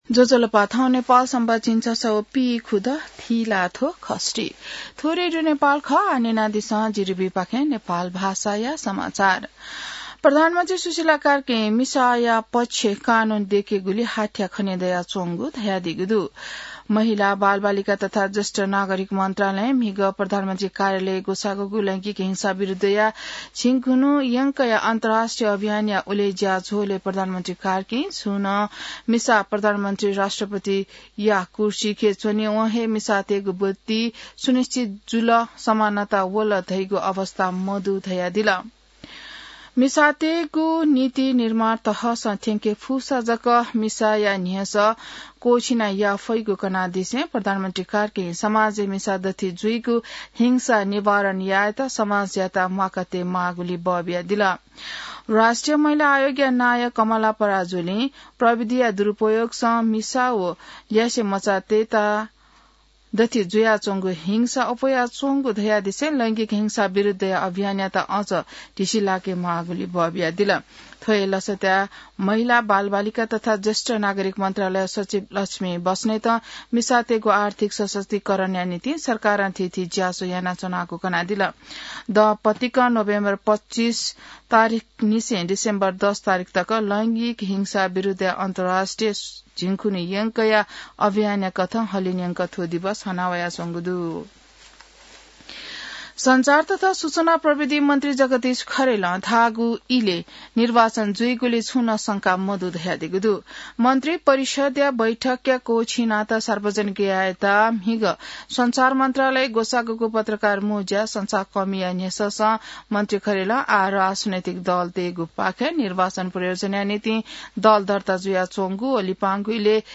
नेपाल भाषामा समाचार : १० मंसिर , २०८२